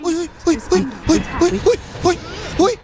A sound effect from Dota 2, the popular competitive multiplayer game — perfect for gaming or esports moments.